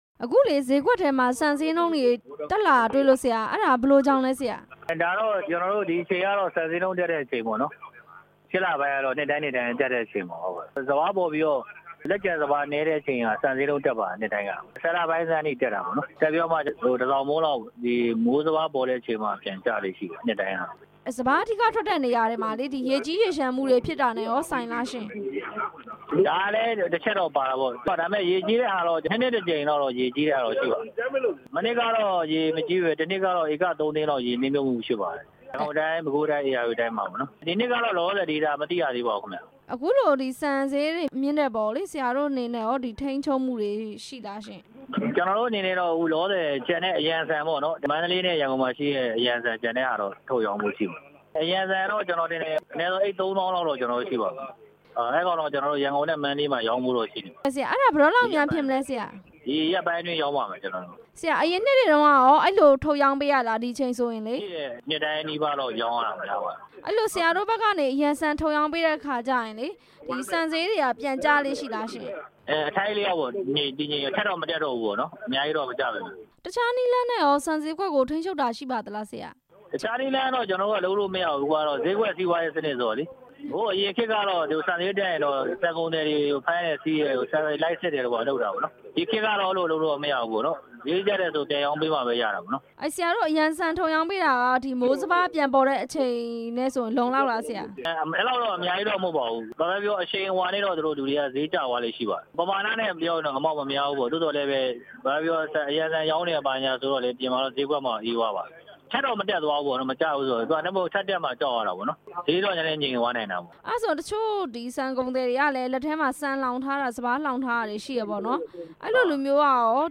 ဆန်ဈေးနှုန်းတွေ မြင့်တက်နေတဲ့အကြာင်း မေးမြန်းချက်